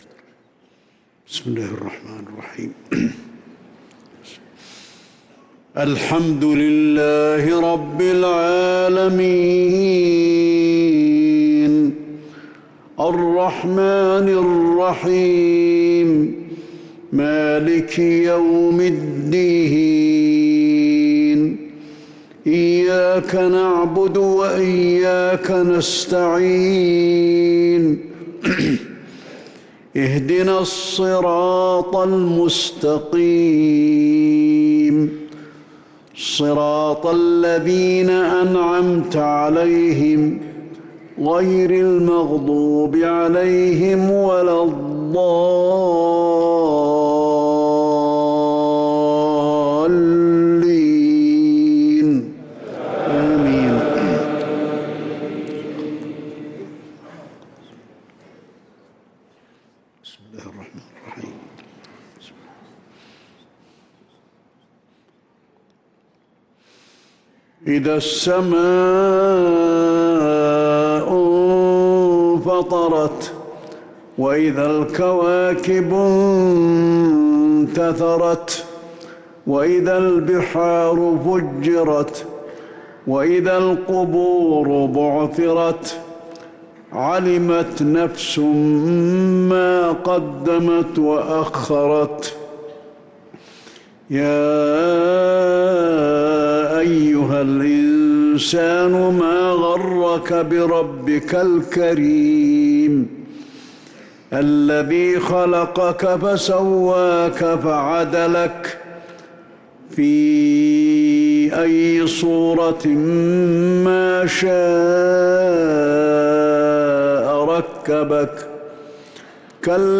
صلاة العشاء للقارئ علي الحذيفي 15 رجب 1445 هـ
تِلَاوَات الْحَرَمَيْن .